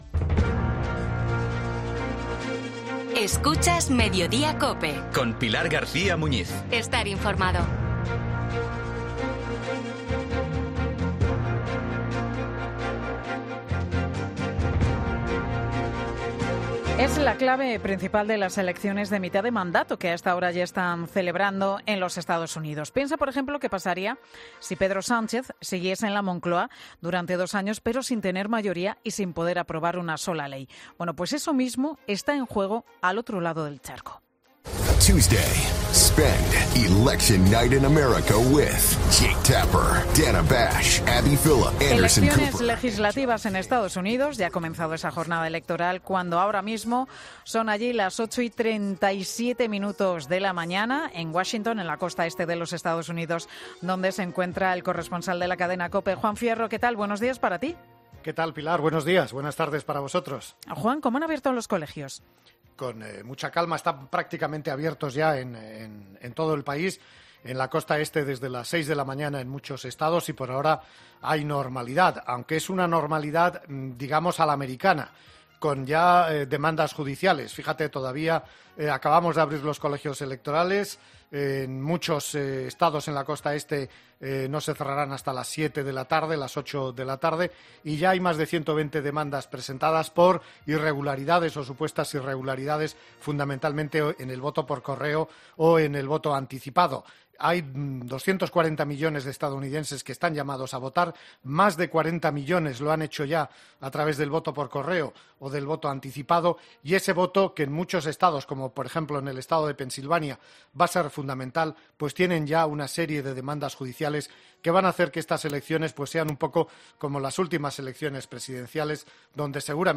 Las elecciones marchan con normalidad, pero a la americana con muchas demandas. Corresponsal